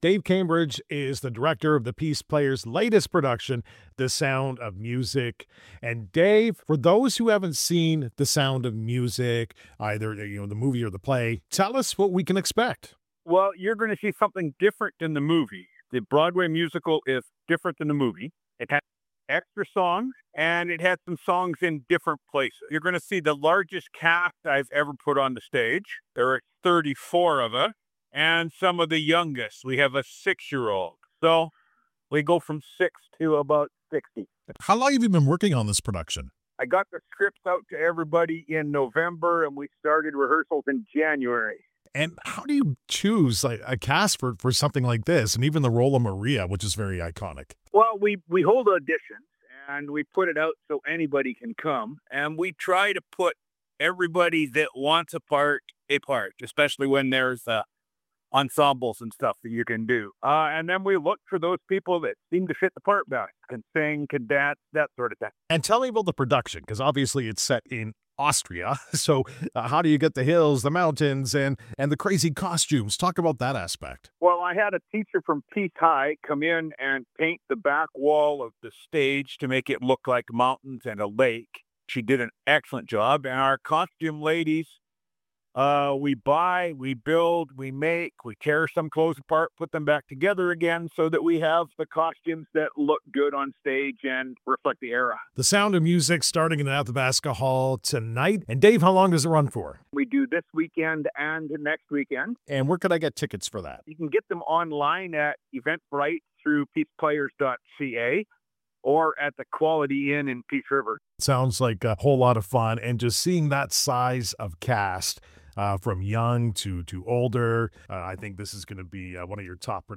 Peace Players The Sound of Music Interview
sound-of-music-interview.mp3